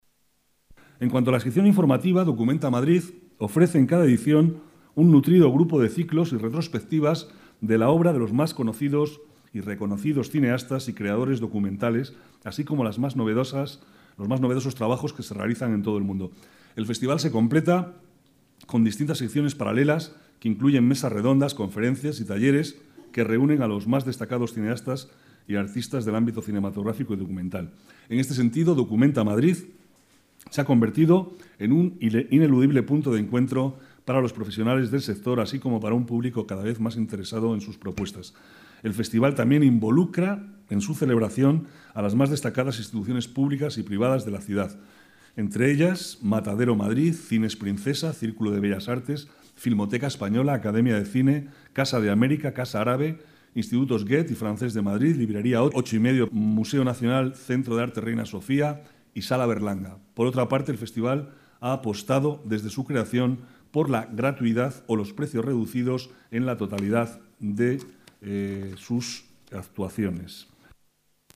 Nueva ventana:Declaraciones del vicealcalde, Manuel Cobo